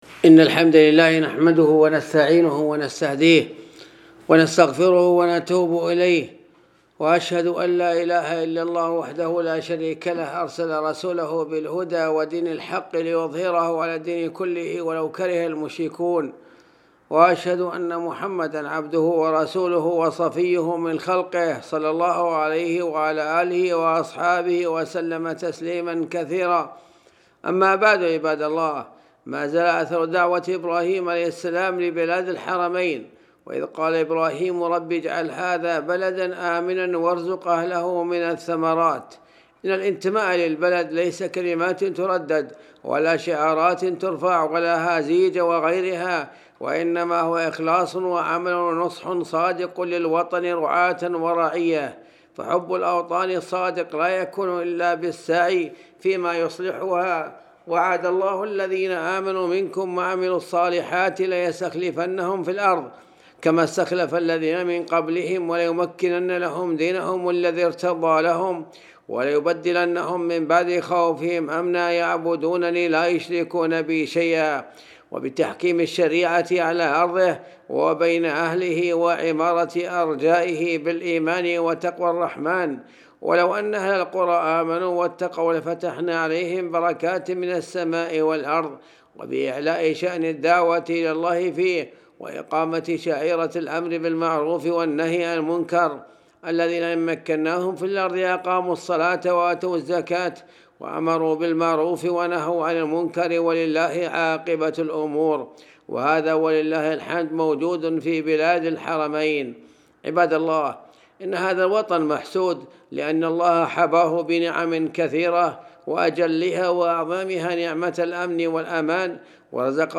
التصنيف : خطب الجمعة تاريخ النشر